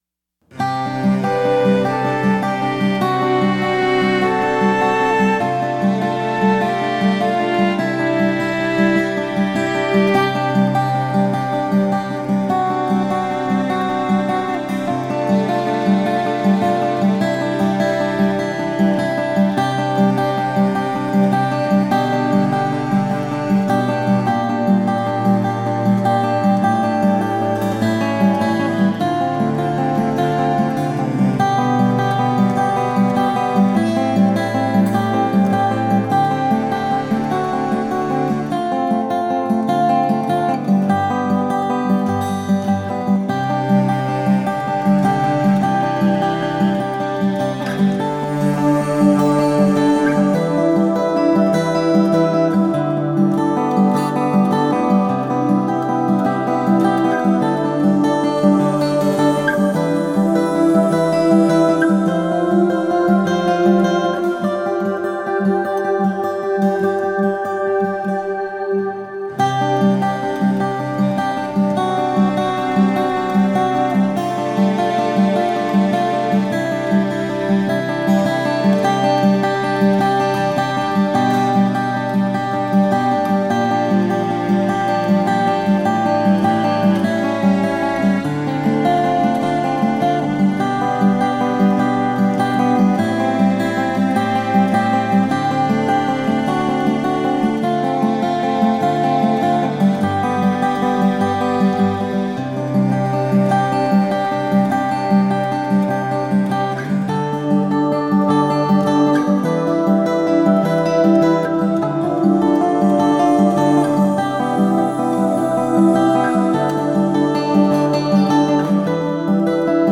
Home Recording